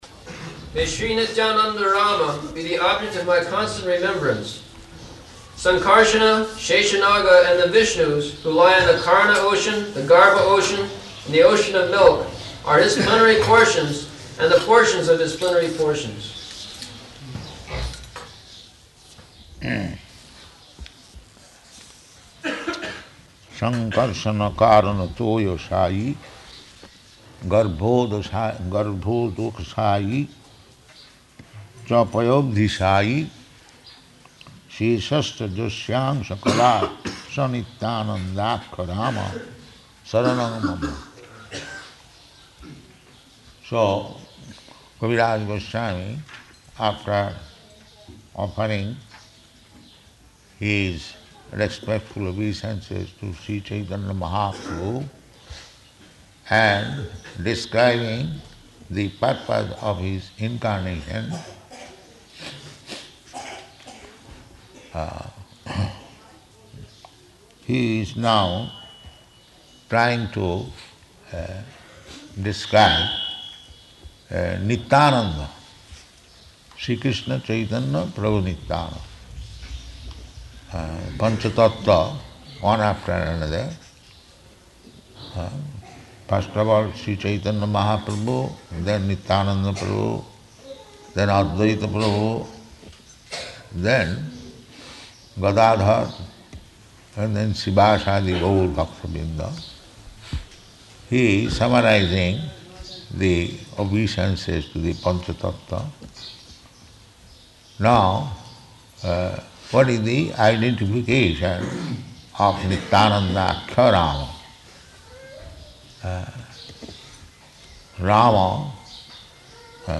Location: Māyāpur